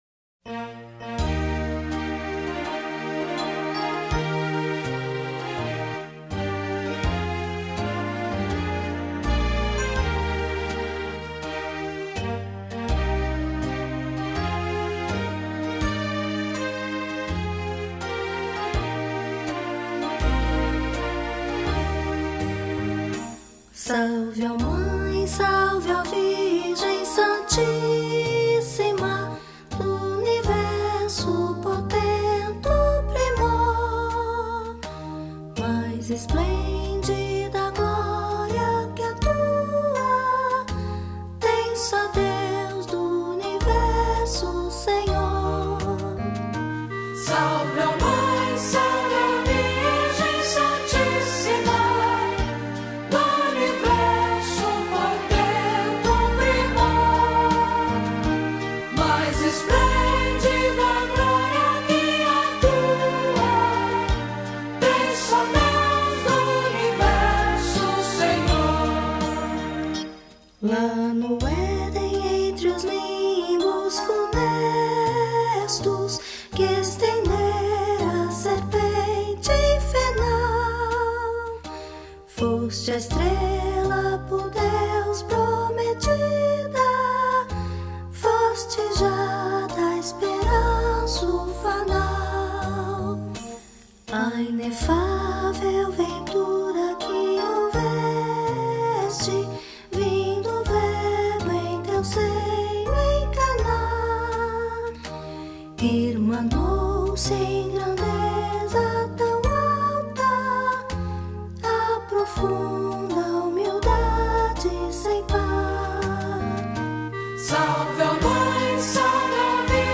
Hino-de-Nossa-Senhora-Aparecida.mp3